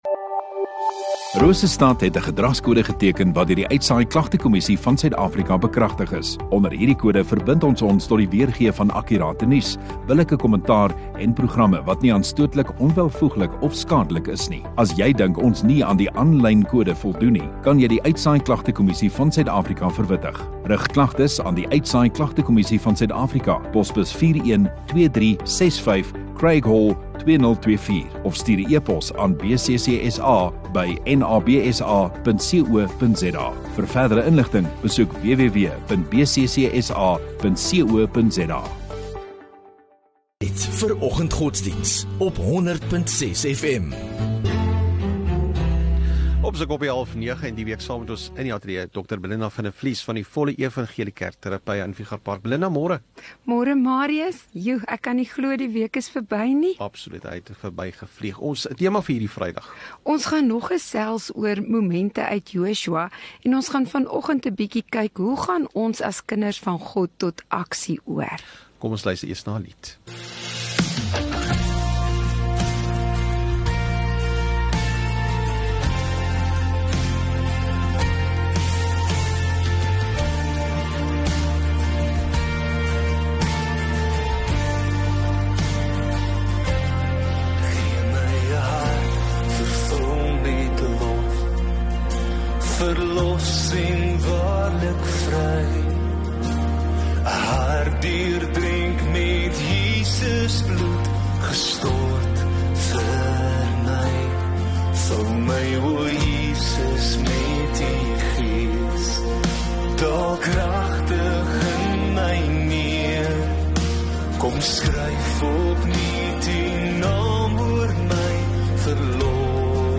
29 Nov Vrydag Oggenddiens